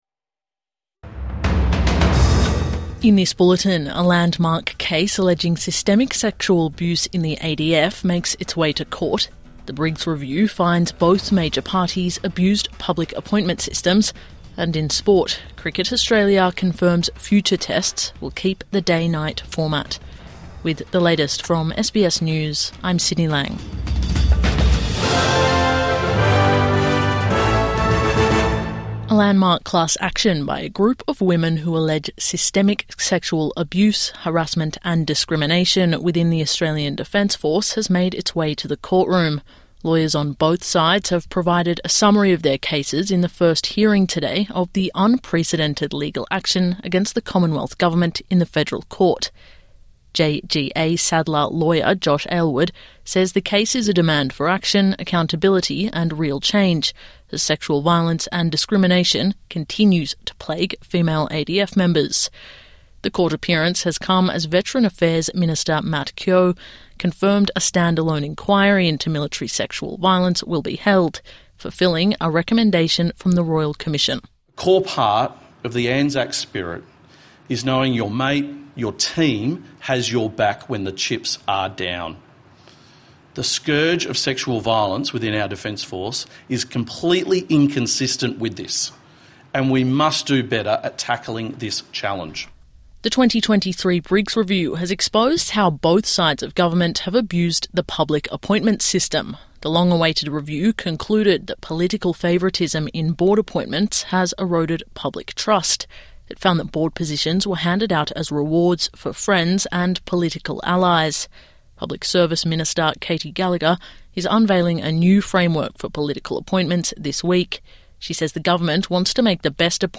Landmark ADF sexual abuse class action underway| Evening News Bulletin 2 December 2025